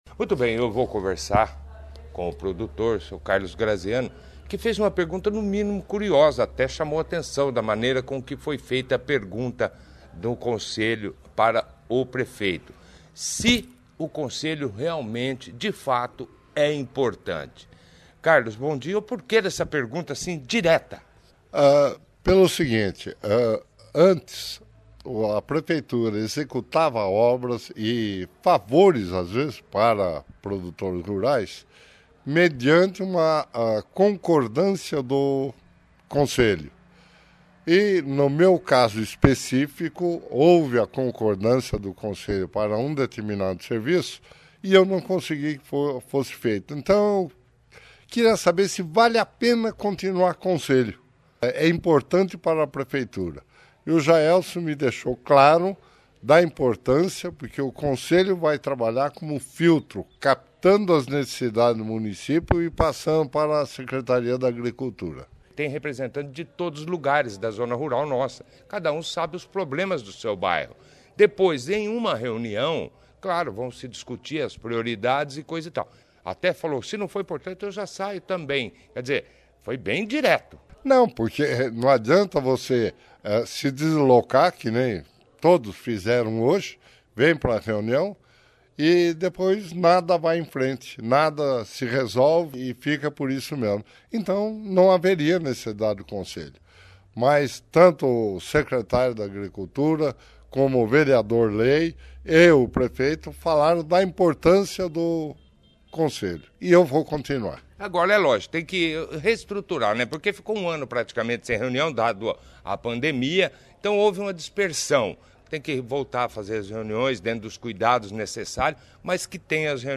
A reunião foi foi destaque a 1ª edição do jornal Operação Cidade desta terça-feira